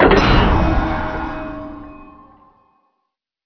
sound / doors / eldertry.wav